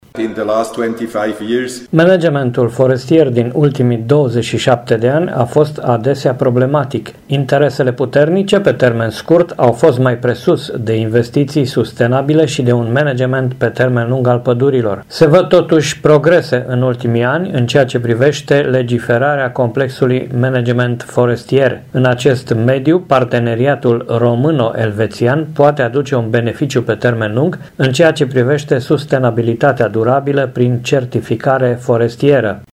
Aula Universității „Transilvania” din Brașov a găzduit Conferința internațională „Gestionarea durabilă a pădurilor și certificarea forestieră”.
Invitat de onoare al conferinței a fost ambasadorul Confederației Elvețiene în România, Excelența Sa Dl. Urs Herren, care a spus: